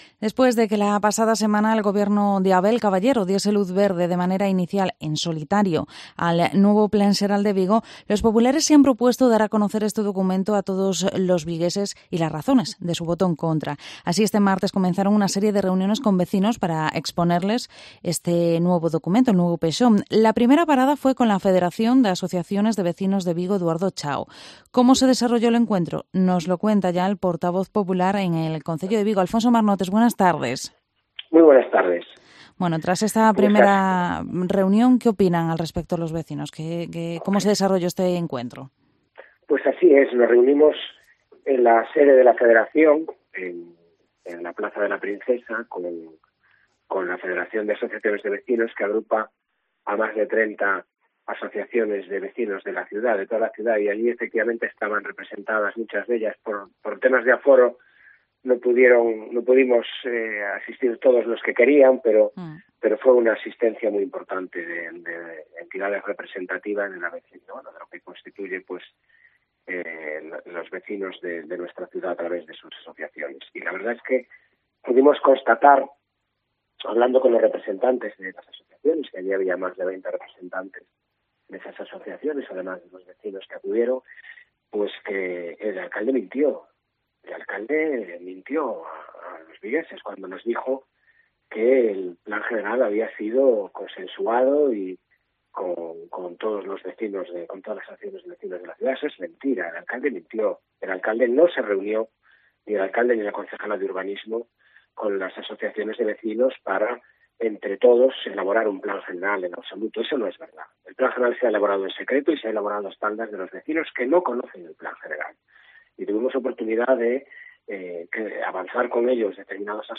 Entrevista a Alfonso Marnotes, portavoz del PP en el Concello de Vigo